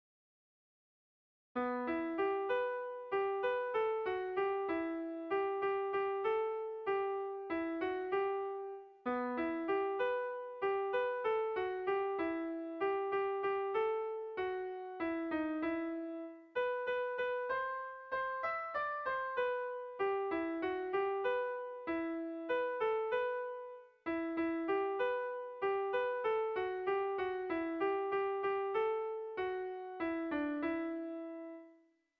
Melodías de bertsos - Ver ficha   Más información sobre esta sección
Zortziko handia (hg) / Lau puntuko handia (ip)
AABA